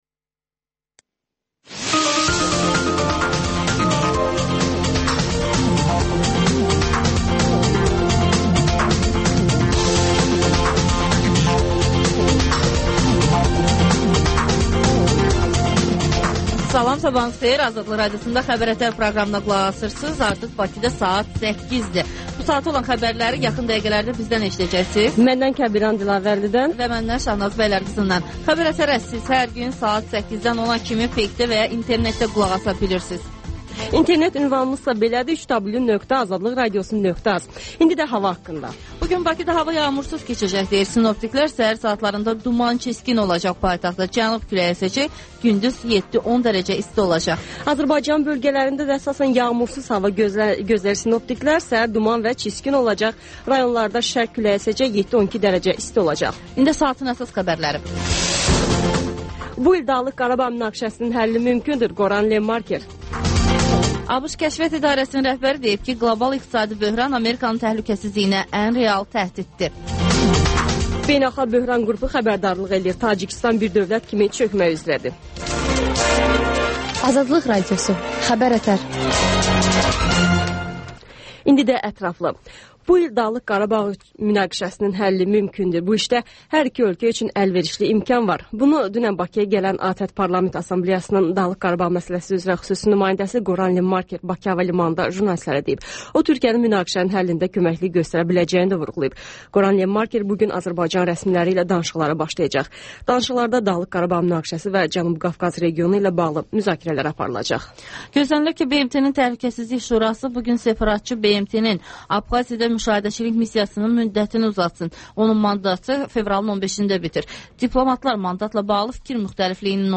Müsahibələr, hadisələrin müzakirəsi, təhlillər Təkrar